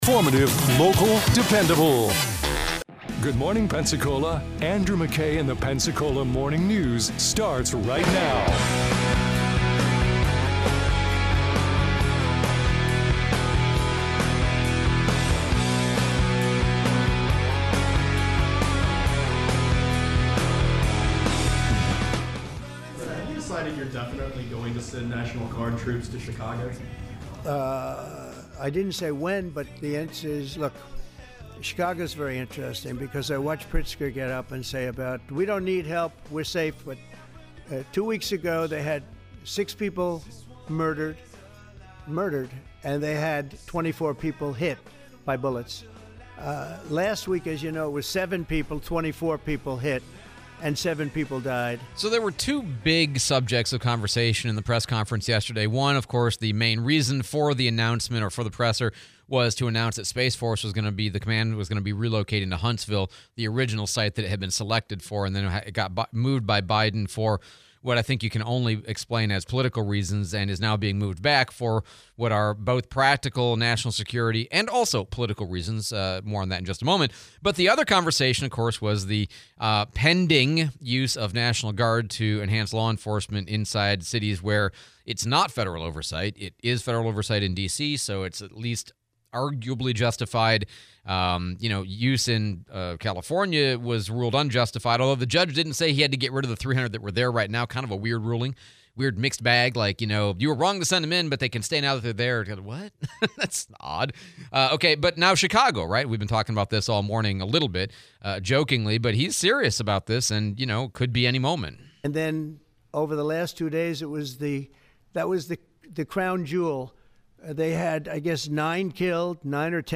Trump press conference continued, Mayor DC Reeves interview